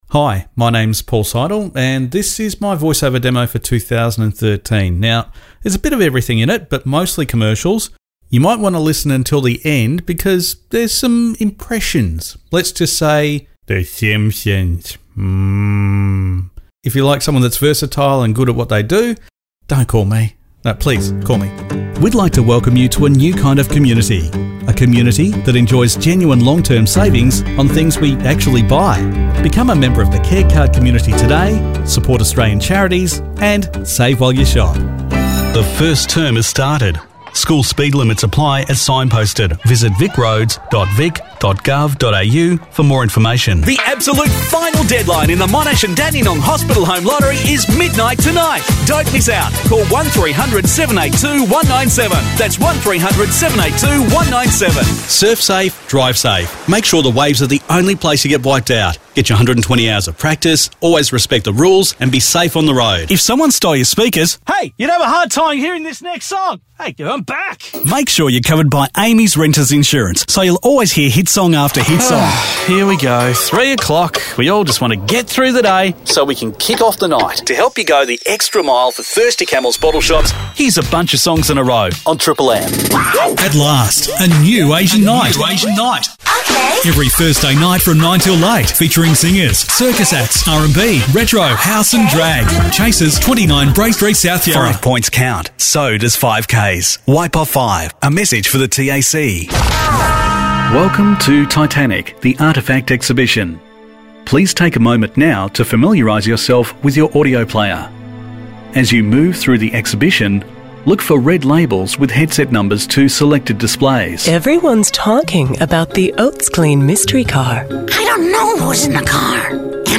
• Masculino
Inglês - Austrália / Nova Zelândia
Voice Over Compilation 2013
Been in the voice over business now for 15 years - and specialise in eLearning narration, along with corporates, TV and radio ads and plenty more ! Have my own dedicated studio and quick turnarounds too.